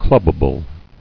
[club·ba·ble]